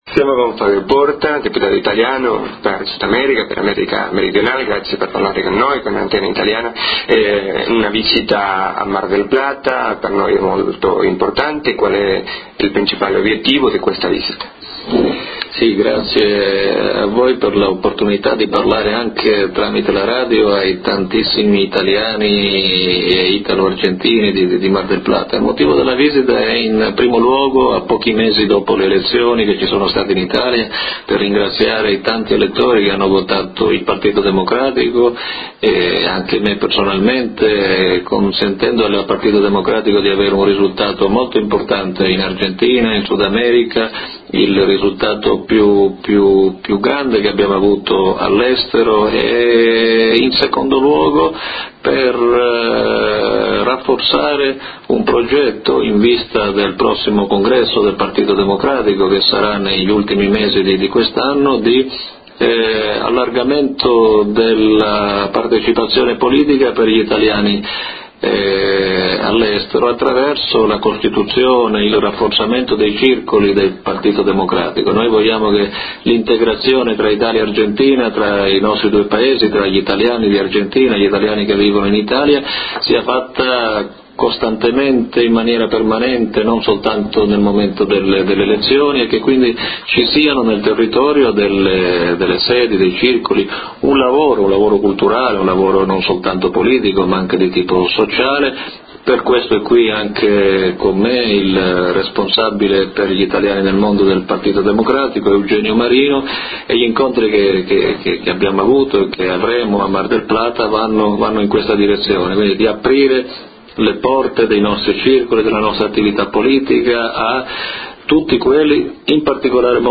Intervista Radio Mar del Plata - Argentina